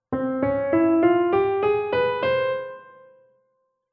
Paradiddle Kapitel 1 → Ungarische (Zigeuner-)Durtonleiter - Musikschule »allégro«
Die ungarische/Zigeuner-Durtonleiter basiert auf der Dur-Tonleiter, die jedoch durch zwei übermäßige Sekundschritte ihre Eigenständigkeit untermauert:
Der 2. und 6. Ton werden jeweils um einen Halbton nach d♭ und a♭ erniedrigt.
TonleiternDurUngarischZigeuner.mp3